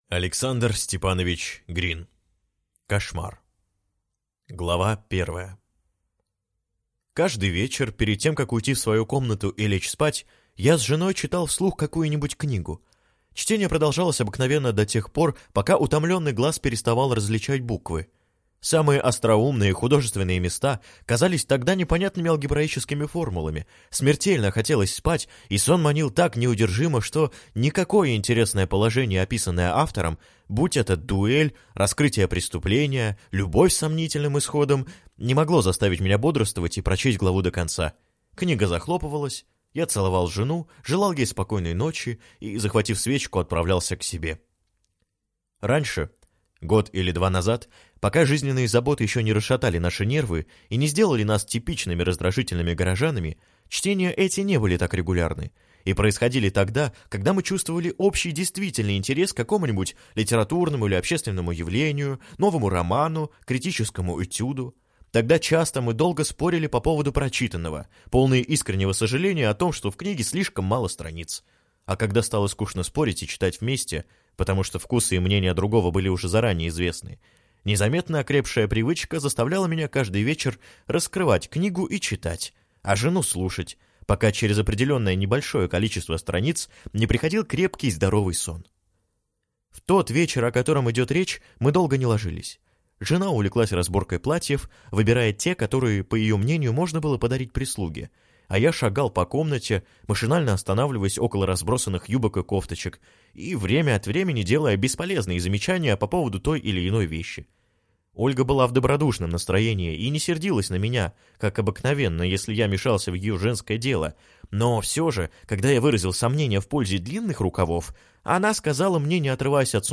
Аудиокнига Кошмар | Библиотека аудиокниг